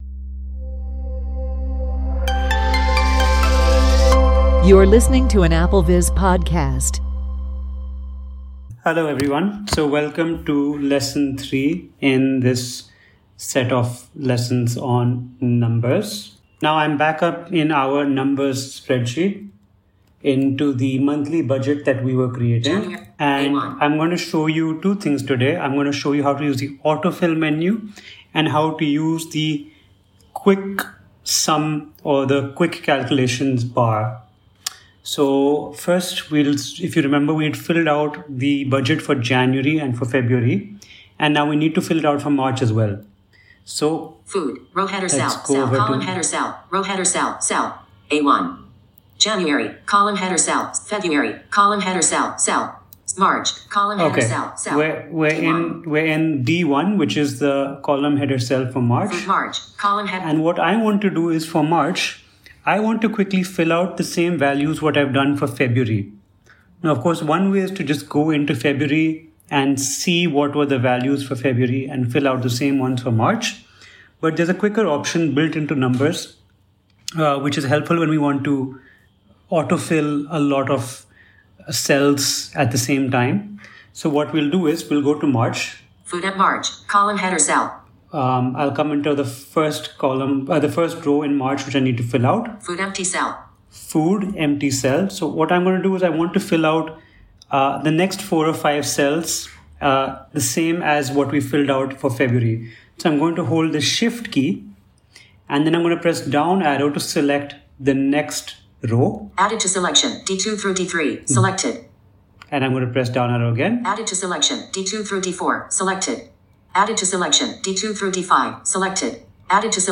Walk-through